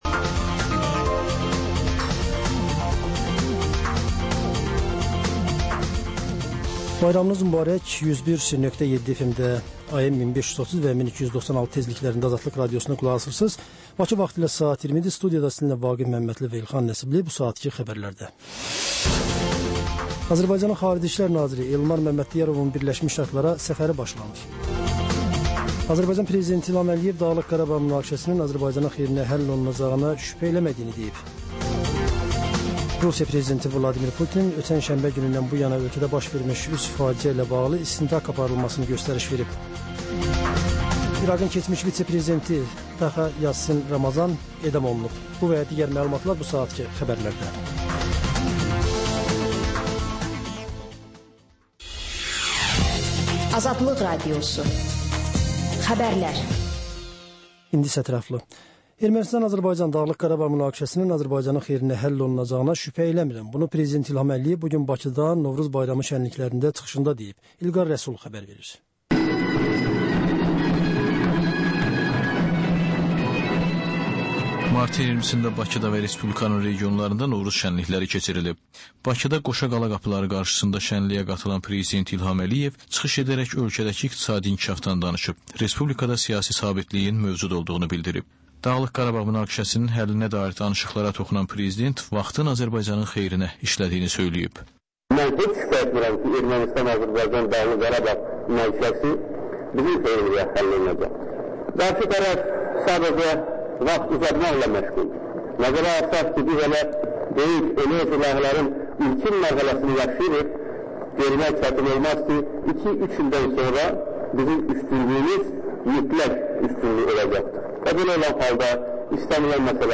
Xəbərlər, müsahibələr, hadisələrin müzakirəsi, təhlillər, sonda ŞƏFFAFLIQ: Korrupsiya haqqında xüsusi veriliş.